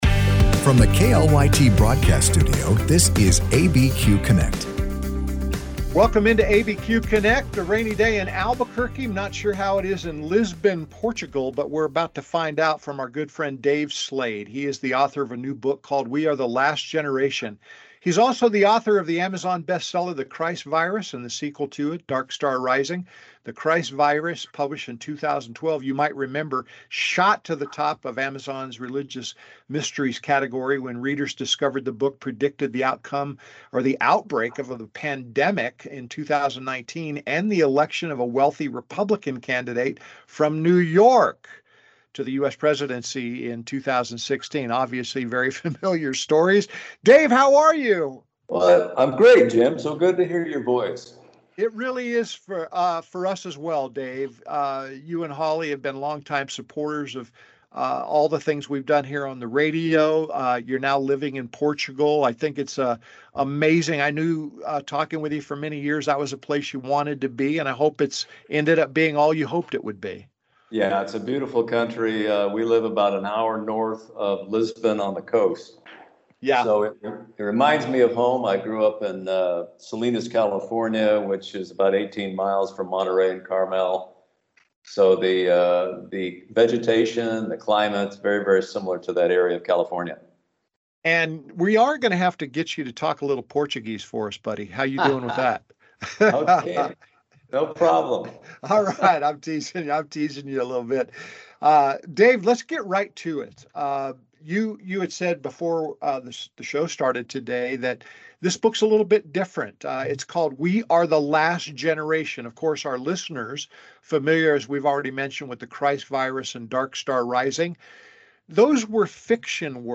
Live, local and focused on issues that affect those in the New Mexico area. Tune in for conversations with news makers, authors, and experts on a variety of topics.